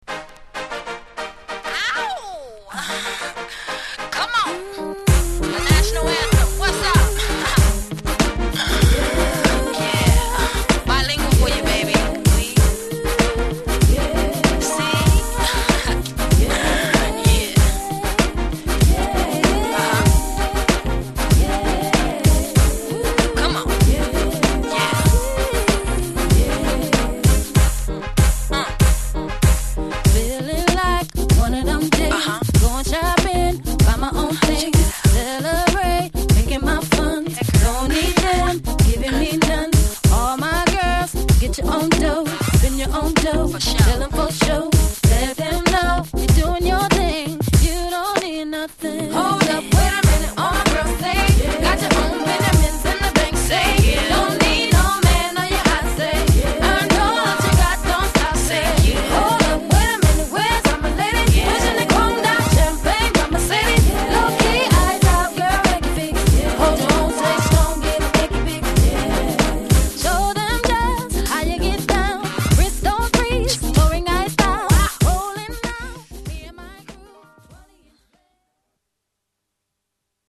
Genre: #R&B
Sub Genre: #2000s